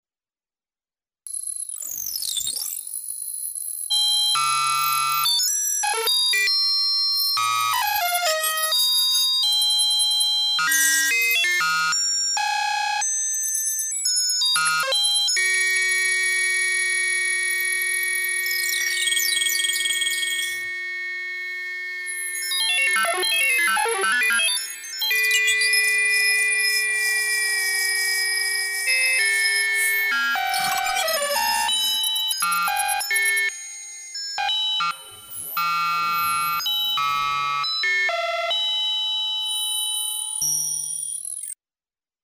ヴァイオリンを演奏せず、ヴィンテージシンセと電気オルガンなどの機材を使用して、作品を構成しました。